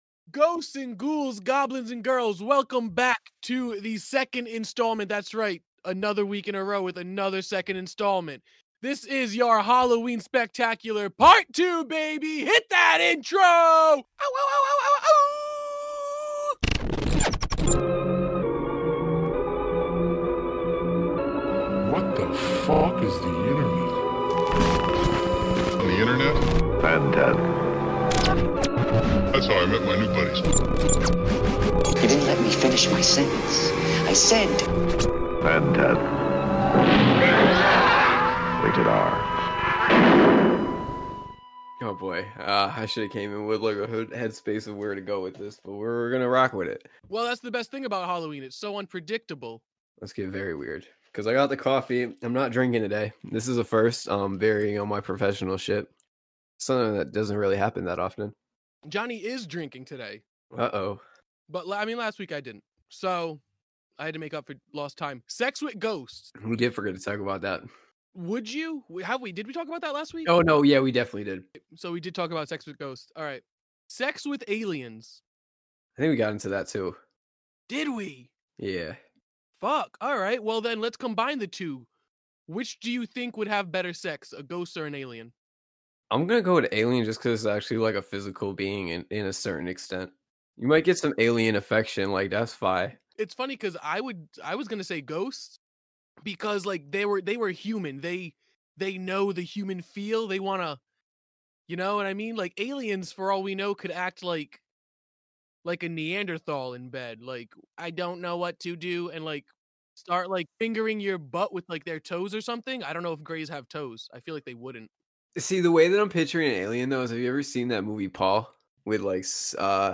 Two boys join forces to summon Halloween 4 months early! With a special interview with The Invisible Man, the boys also talk Blair Witch, goblin facts, a Hennymilk horror story, and more!